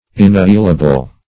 Search Result for " inappealable" : The Collaborative International Dictionary of English v.0.48: Inappealable \In`ap*peal"a*ble\, a. Not admitting of appeal; not appealable.